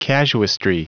Prononciation du mot casuistry en anglais (fichier audio)
Prononciation du mot : casuistry